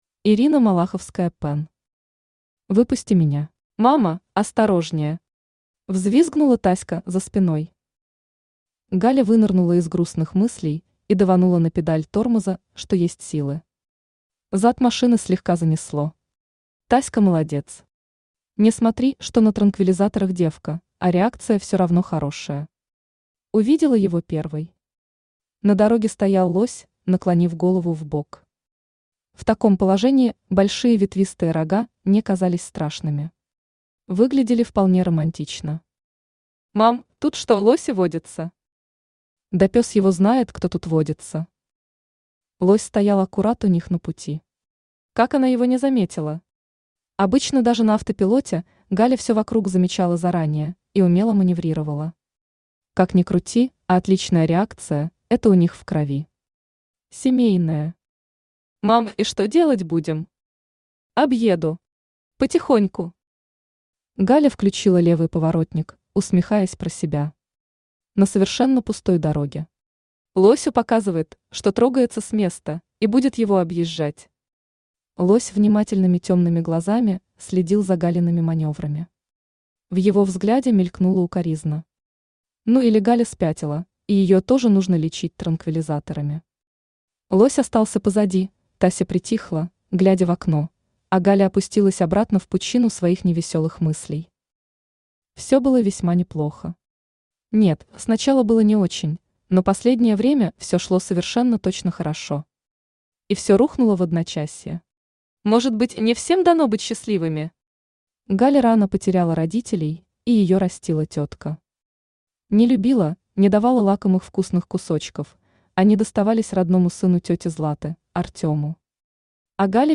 Аудиокнига Выпусти меня | Библиотека аудиокниг
Aудиокнига Выпусти меня Автор Ирина Малаховская-Пен Читает аудиокнигу Авточтец ЛитРес.